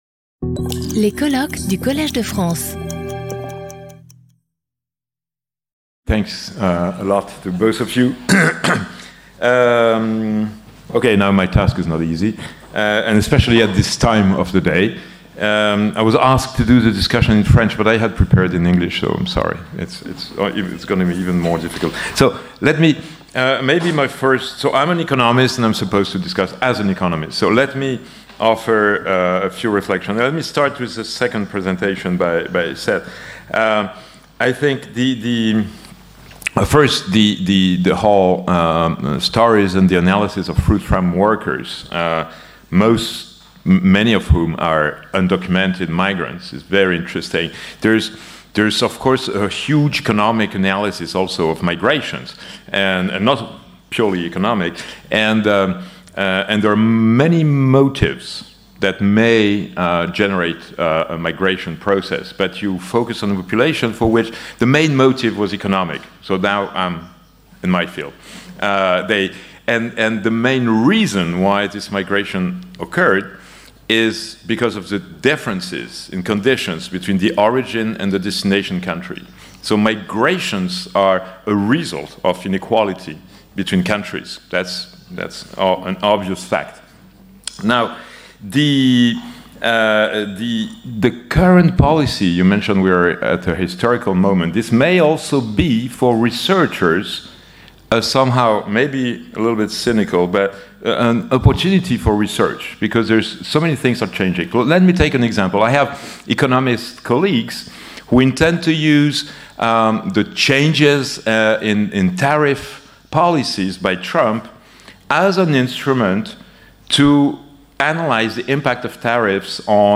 This video is offered in a version dubbed in French.